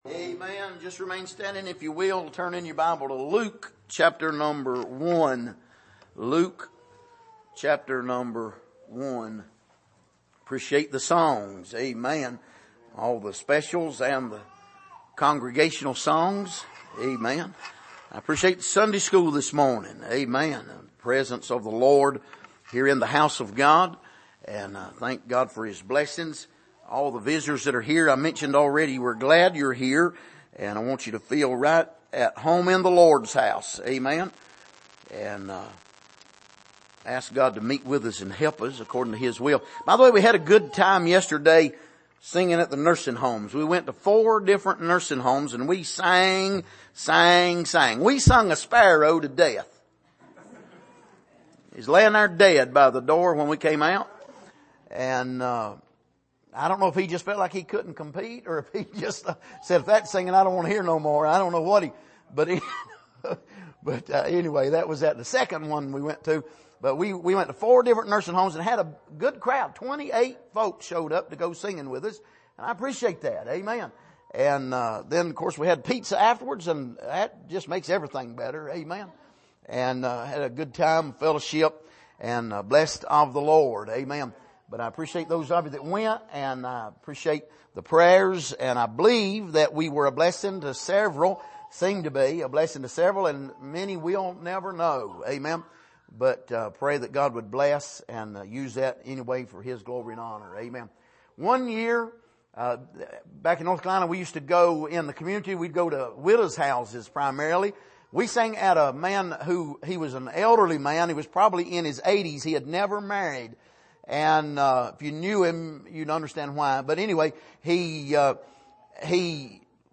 Passage: Luke 1:1-4 Service: Sunday Morning